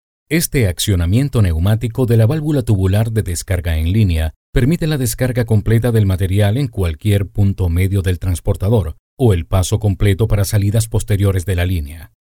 Locutor profesional venezolano con manejo de acento neutro del español latinoamericano, voice over talent
Sprechprobe: Industrie (Muttersprache):